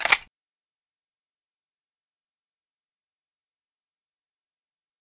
Index of /server/sound/weapons/remington
hammer.mp3